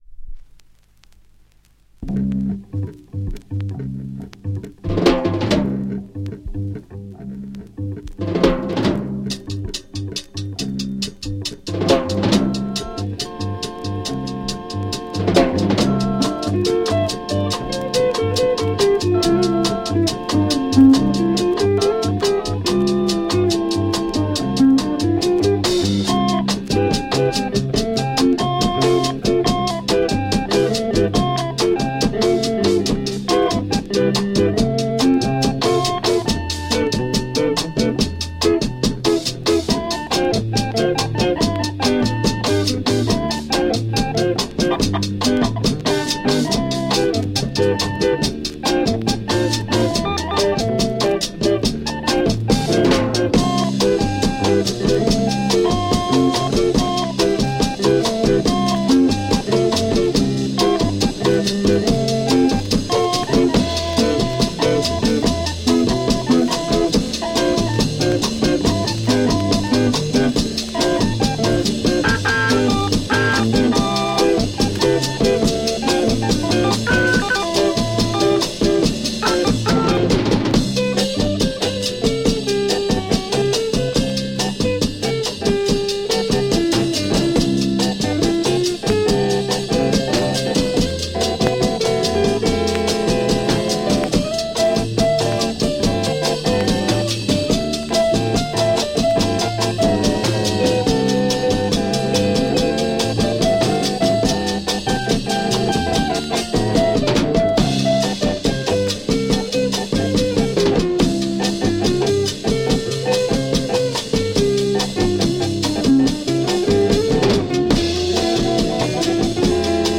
Mega rare Portuguese instro Latin organ Jazz